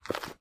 STEPS Dirt, Walk 24.wav